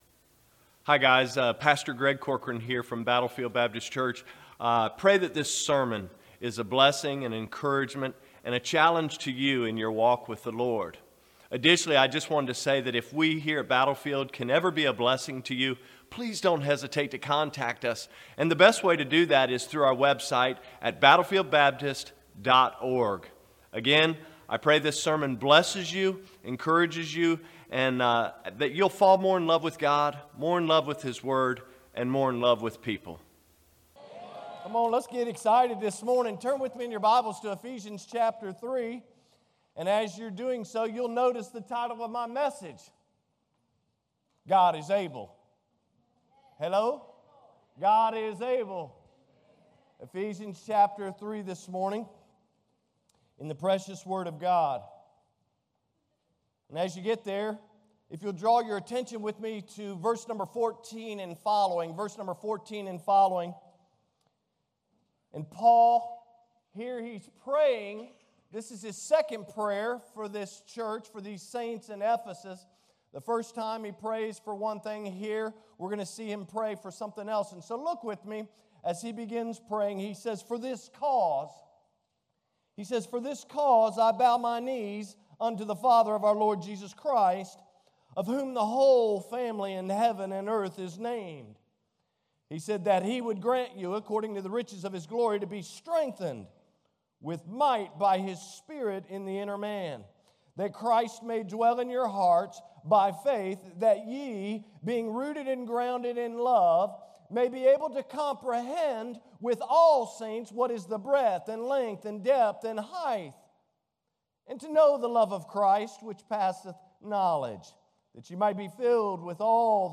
Sermons | Battlefield Baptist Church